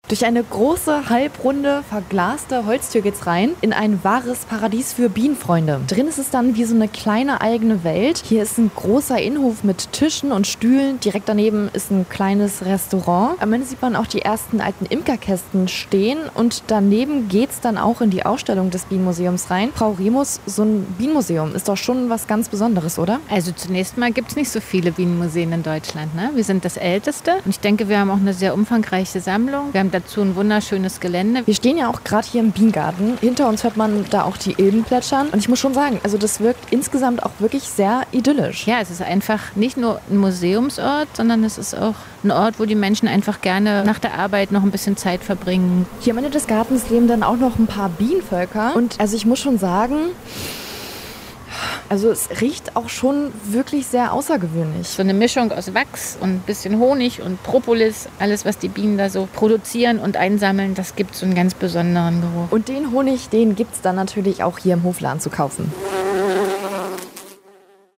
Die Landeswelle Thüringen war bei uns zu Gast!